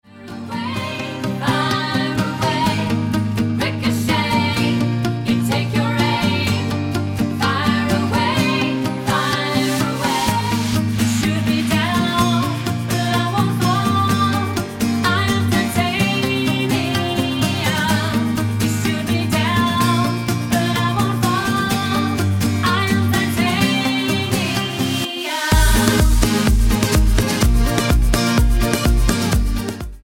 Tonart:Eb mit Chor
Die besten Playbacks Instrumentals und Karaoke Versionen .